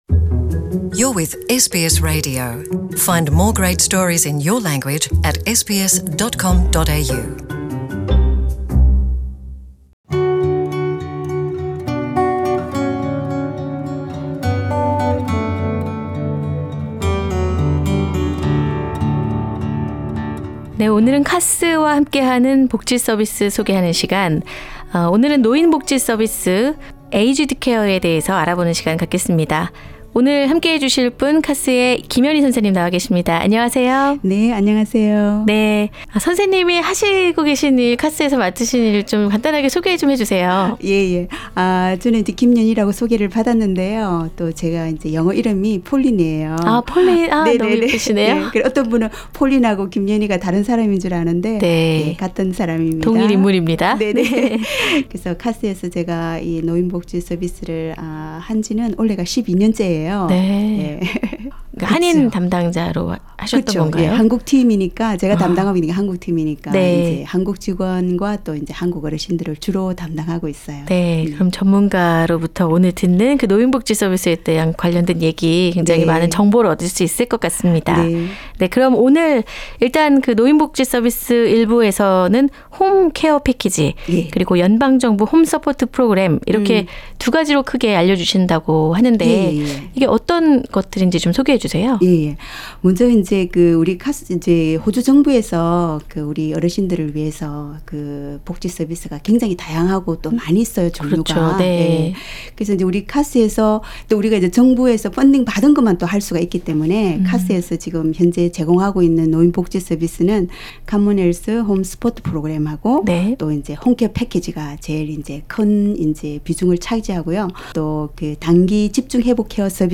[The full interview is available on the podcast above] CASS Aged care Source: CASS Share